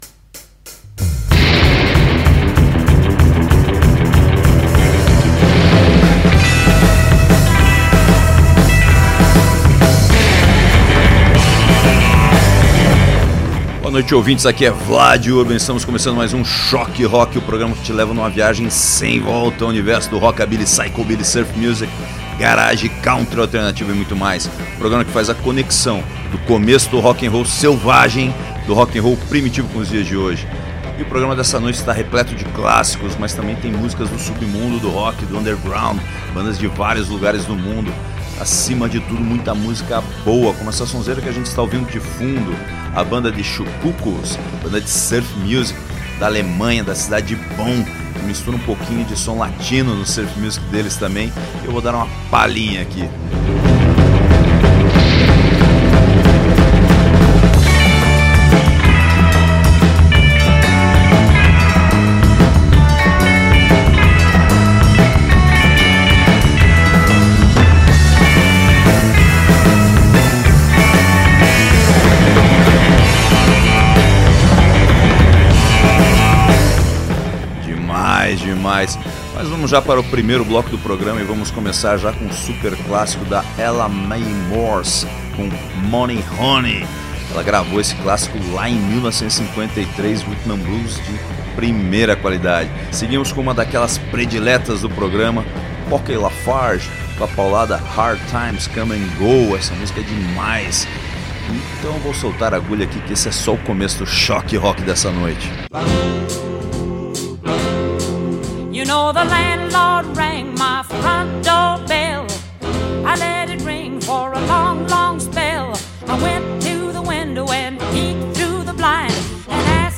o resgate do melhor do rock’n’roll dos anos 50